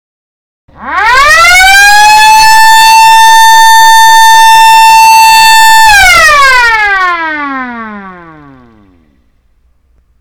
Sirene escola/ponto | BRALARMSEG
SIRENE ELETROMECÂNICA PARA PROGR. HORÁRIOS
Tipo de som: Contínuo(monotonal);
114dB(+/-)10%